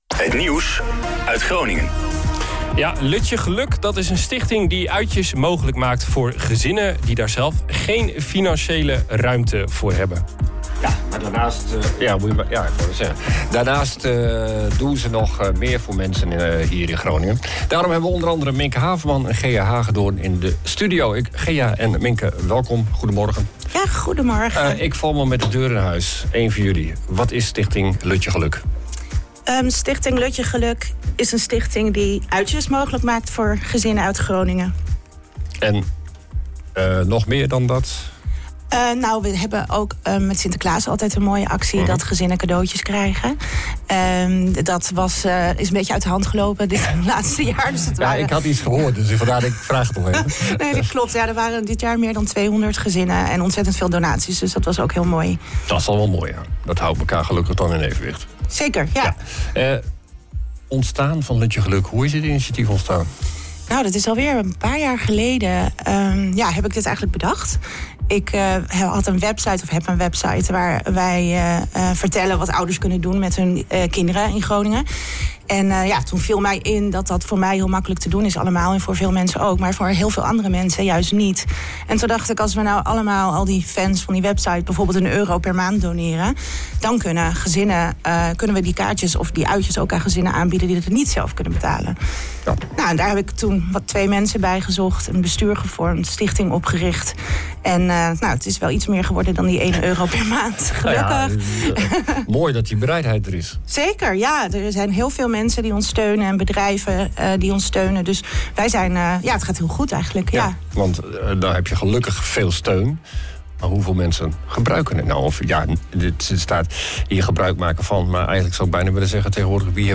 We hadden Lutje Geluk live in Ochtendshow om te vertellen over wat zij allemaal doen. 2023-01-06 Lutje geluk ochtendshow OOG Oog Ochtendshow